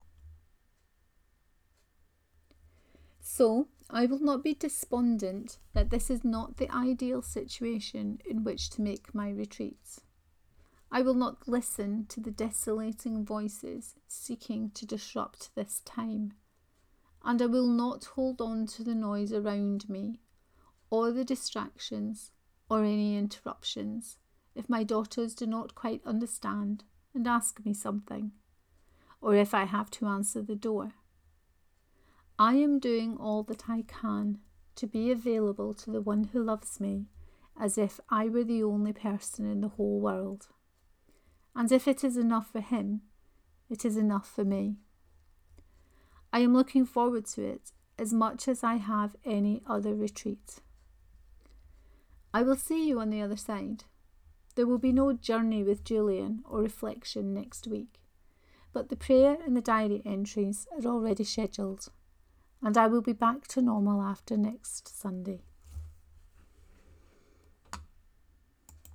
IGR 2020 3: Reading of this post.